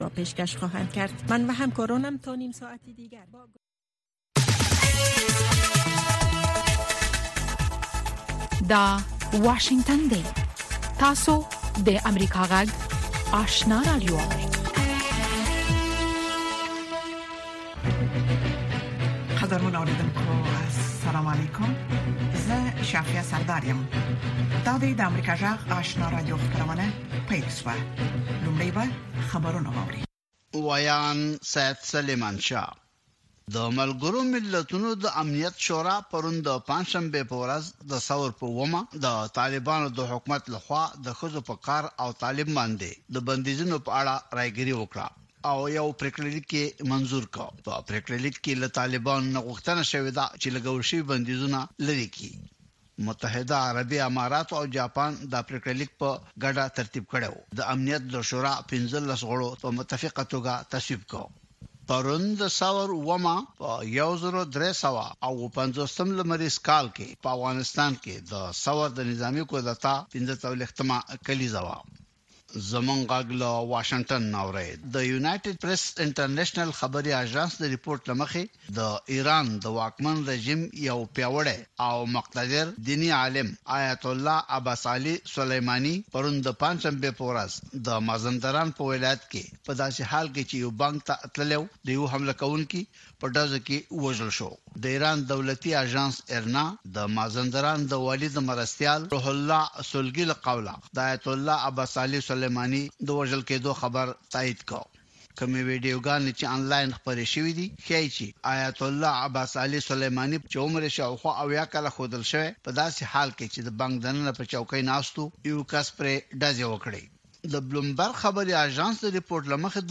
دویمه سهارنۍ خبري خپرونه
په سهارنۍ خپرونه کې د افغانستان او نړۍ تازه خبرونه، څیړنیز رپوټونه او د افغانستان او نړۍ د تودو پیښو په هکله مرکې تاسو ته وړاندې کیږي.